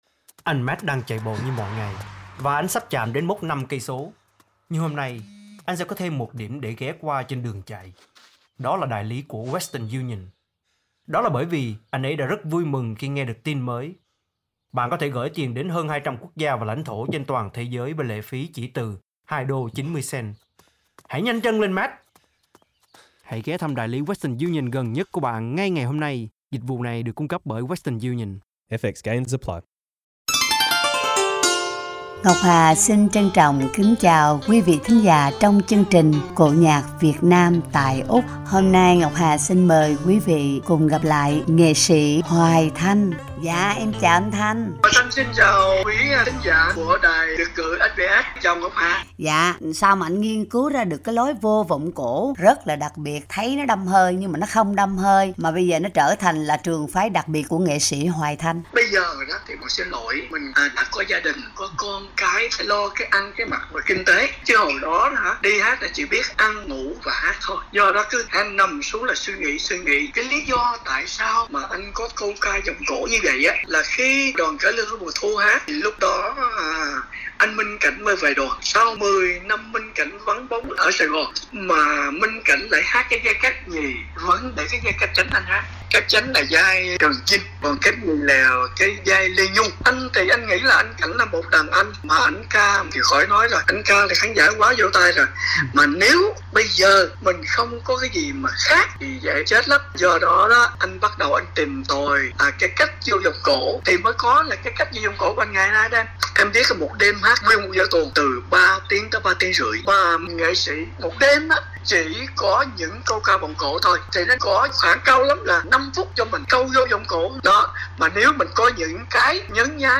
sân khấu cải lương